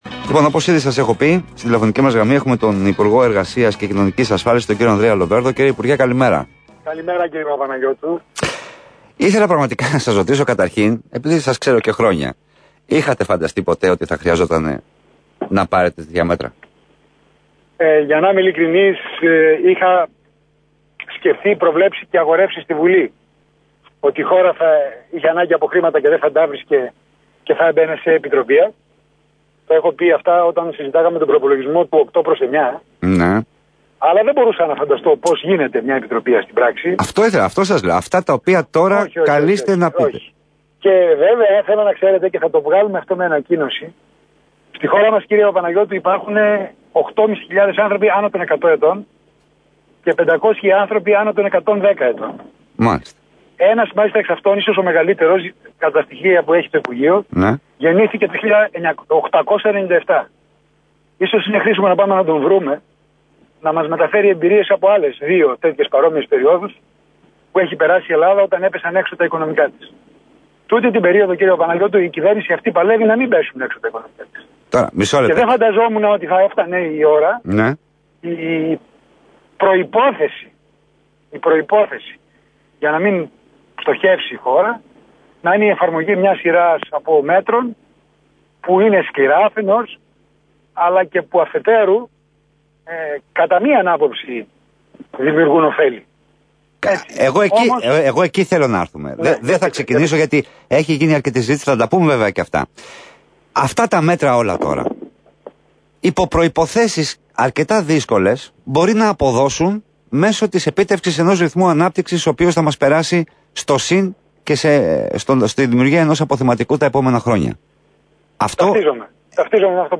Ακούστε τις δηλώσεις του κ. Ανδρέα Λοβέρδου στον ΘΕΜΑ 989